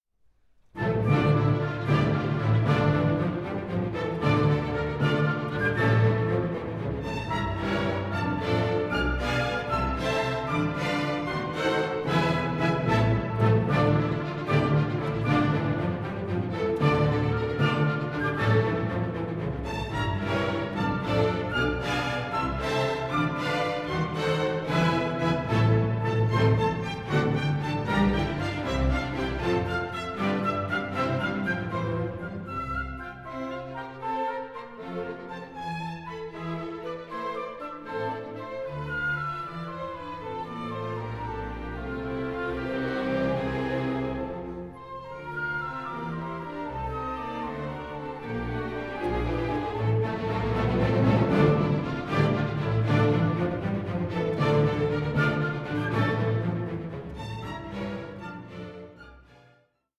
Scherzo 5:47